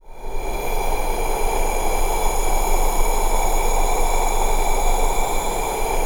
U-NOISEBED.wav